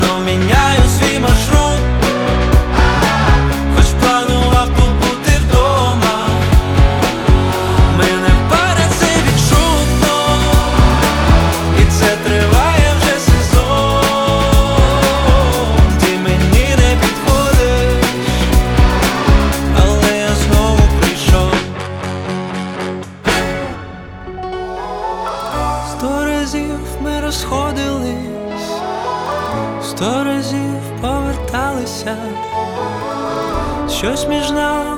Pop
Жанр: Поп музыка / Украинские